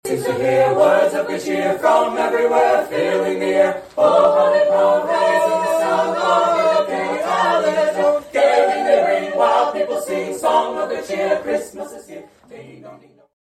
Festive atmosphere present during White Christmas at Red Rocks
Emporia High’s Viva Voce provided the holiday music for people attending White Christmas at Red Rocks.